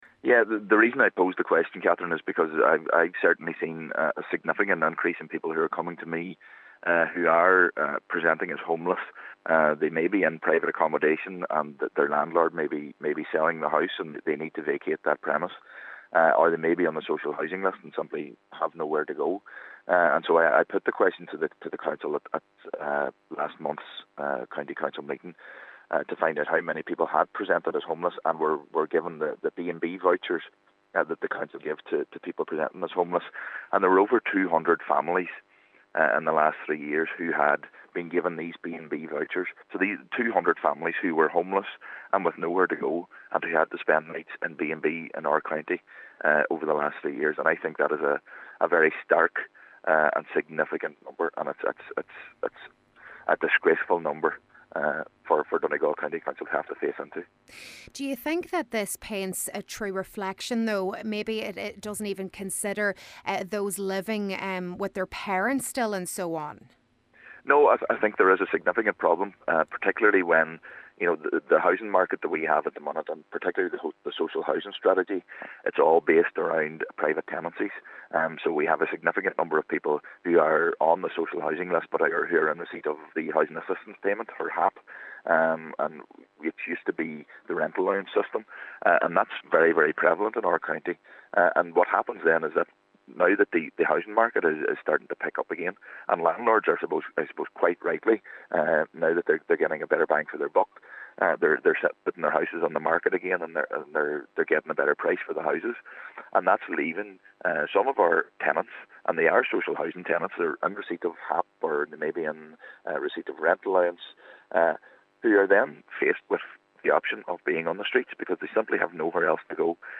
While acknowledging this, Cllr. Gary Doherty says the figures still portray a real issue in the county and this needs to be addressed urgently: